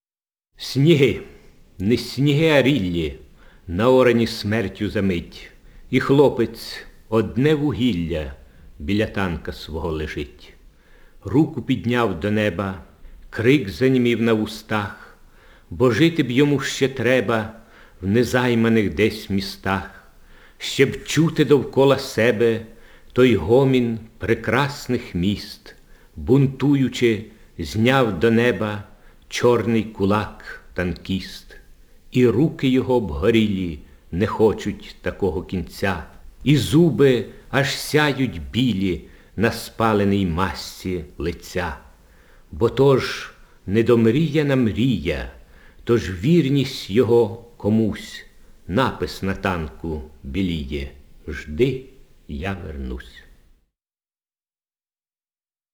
Index of /storage/Oles_Gonchar/Записи голосу Гончара